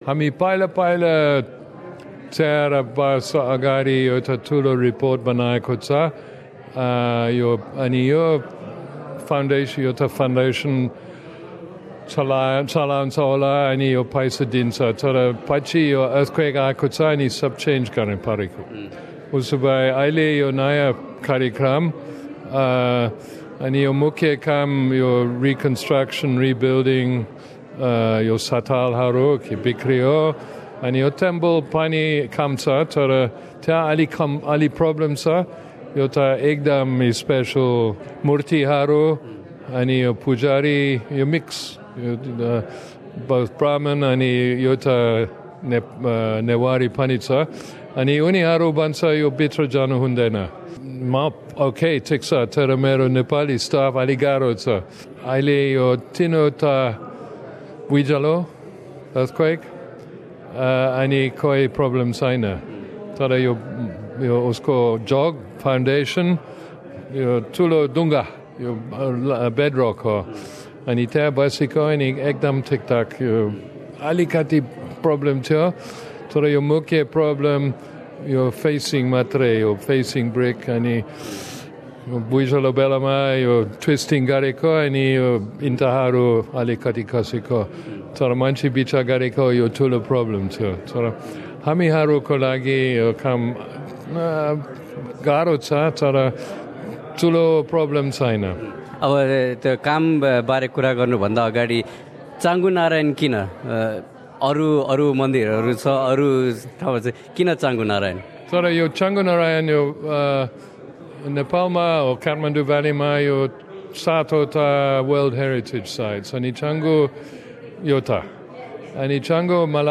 He spoke to SBS Nepali about the importance of rebuilding the temple and the duration it may take to complete the project.